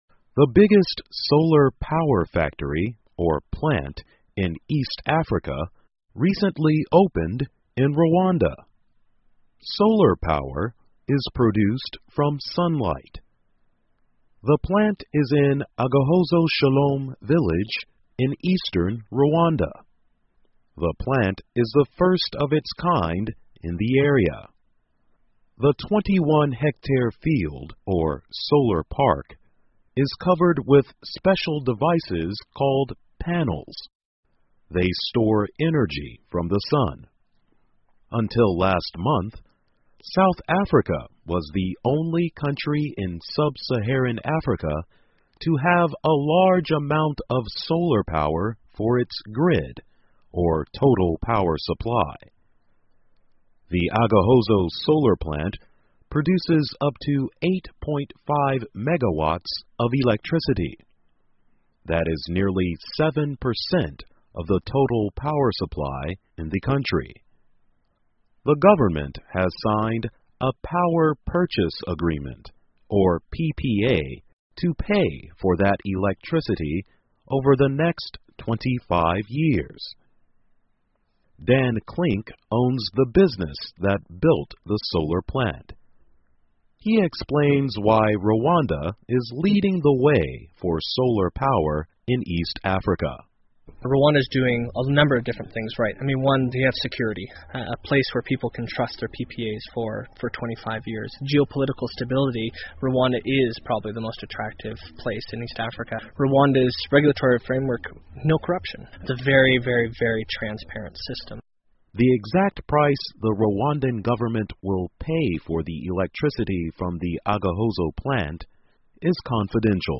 VOA慢速英语2014 卢旺达开办东非最大的太阳能发电厂 听力文件下载—在线英语听力室